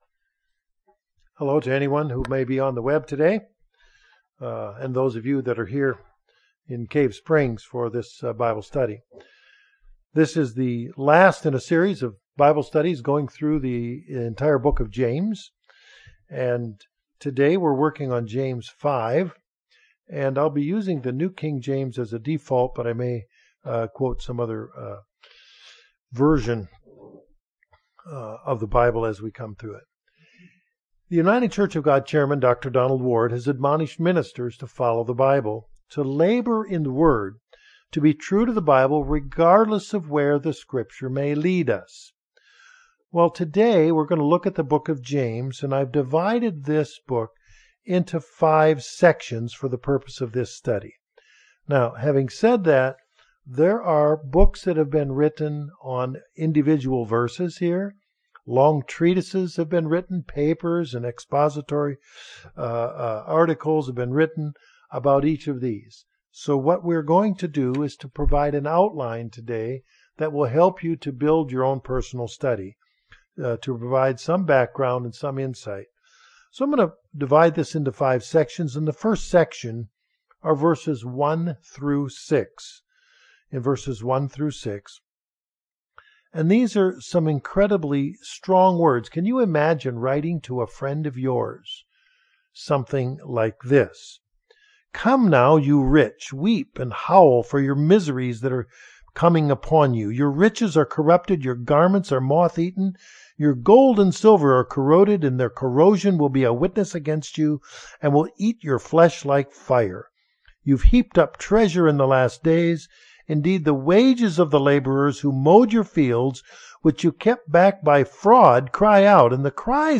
We complete our Bible Study on the Book of James, going through chapter 5. Includes an interactive closing session.